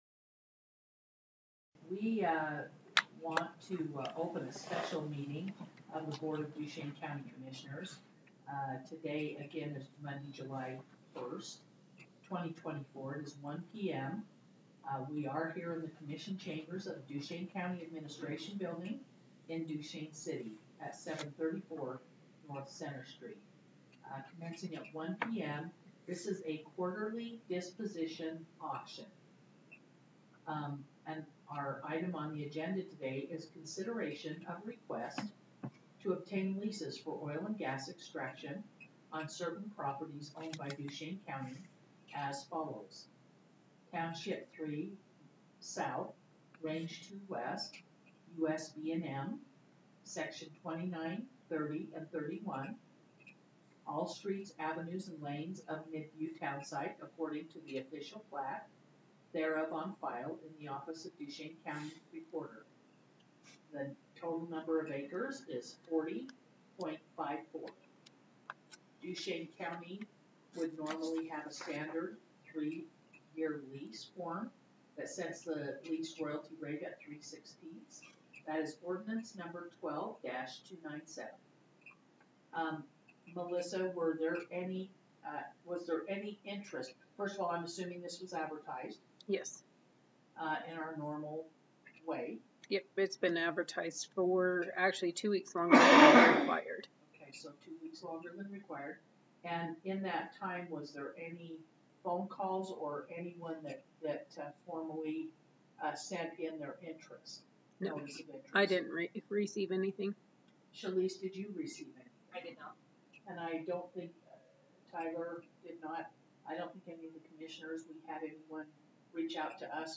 Notice of Special Meeting of the Board of County Commissioners of Duchesne County
NOTICE OF SPECIAL MEETING OF THE BOARD OF COUNTY COMMISSIONERS OF DUCHESNE COUNTY PUBLIC NOTICE is hereby given that the Board of Duchesne County Commissioners will meet in special public session on Monday, July 1, 2024, in the Commission Chambers of the Duchesne County Administration Building in Duchesne, Utah, commencing at 1:00 p.m. for A Quarterly Disposition Auction.